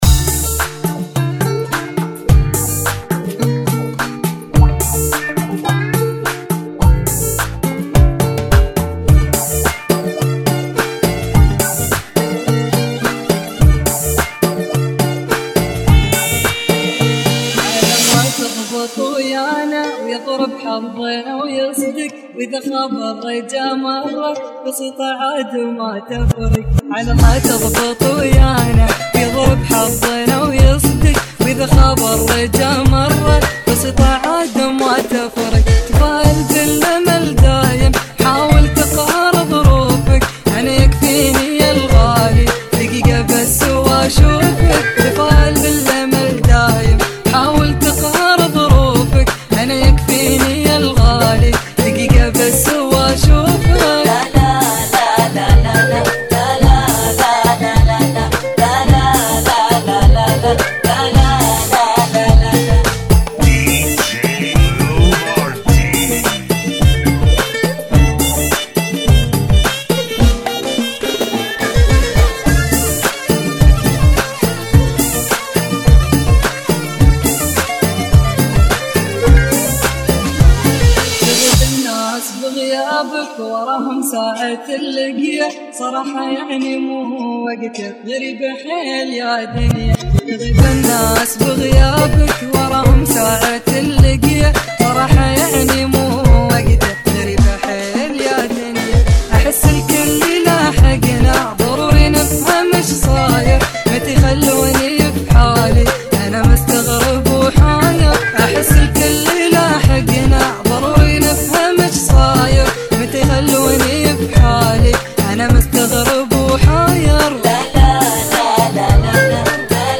Funky ( Bbm 106